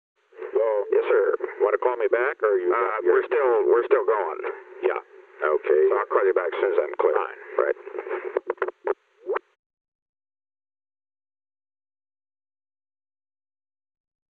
Secret White House Tapes
Location: White House Telephone
The President talked with John D. Ehrlichman.